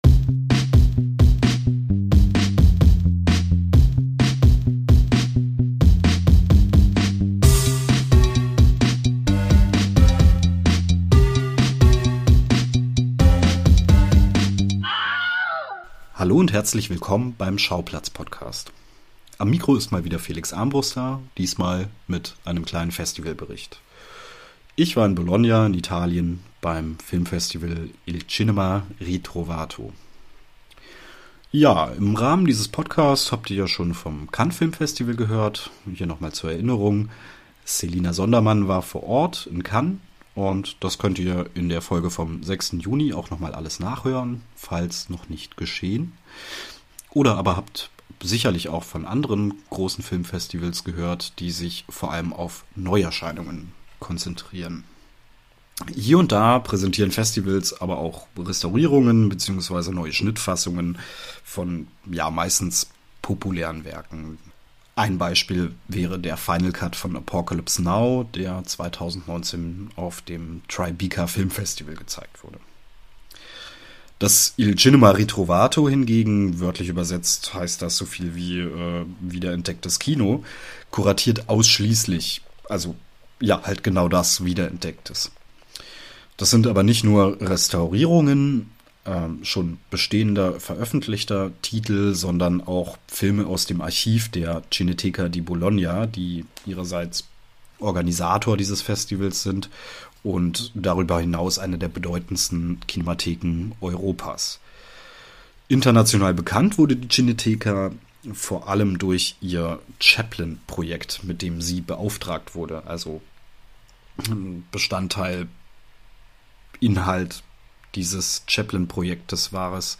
Mit im Gepäck: zwei Connaisseurs, die ihm fleißig helfen das alles einzuordnen. Weil das sehr viel war, hier unsere Highlights aus einer Woche!